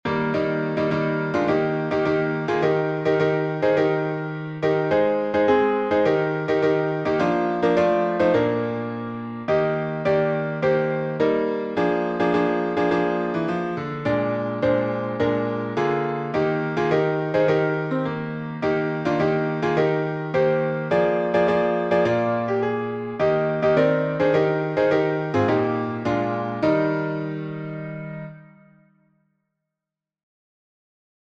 Words by Judson W. Van de Venter Winfield S. Weeden Key signature: E flat major (3 flats) Time signature: 4/4 Meter